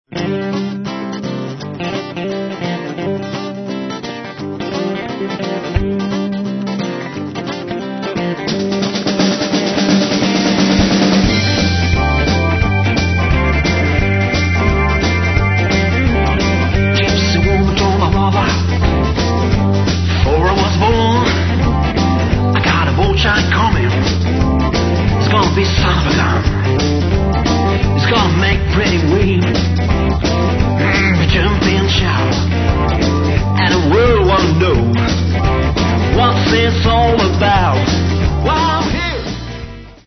17 Titres country et Rock n’ roll